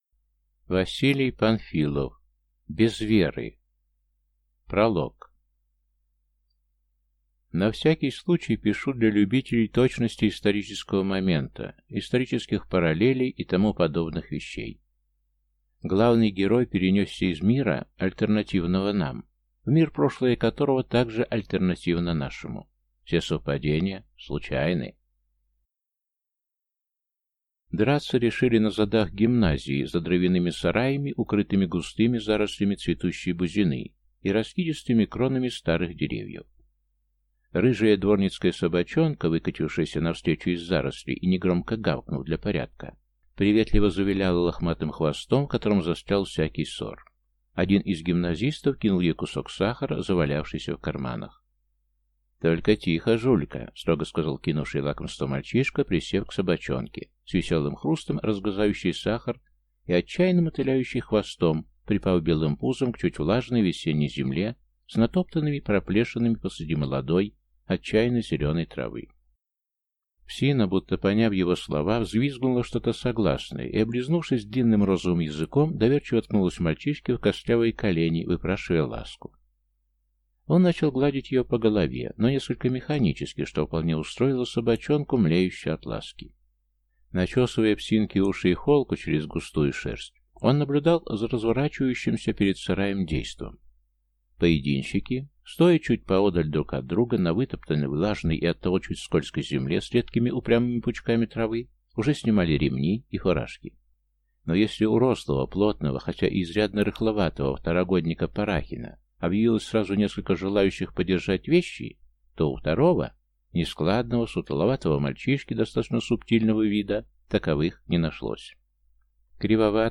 Аудиокнига Без Веры…
Прослушать и бесплатно скачать фрагмент аудиокниги